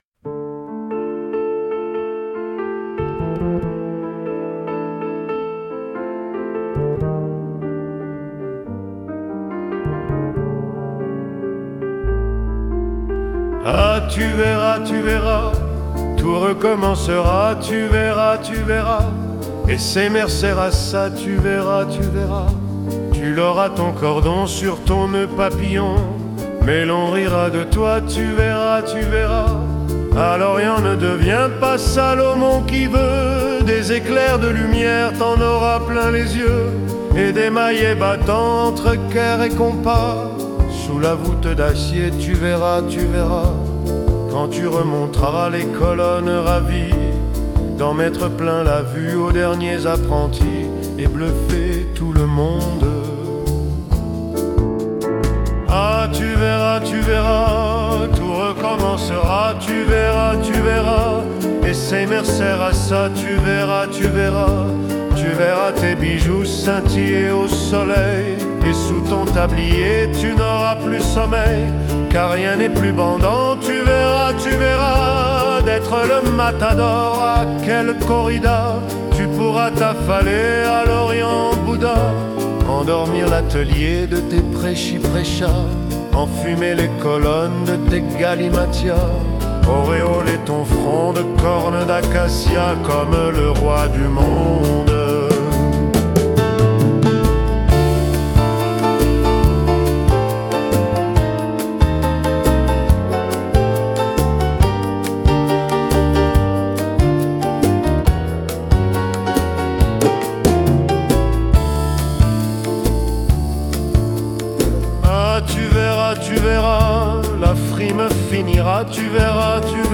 Enregistrement public Festival 2013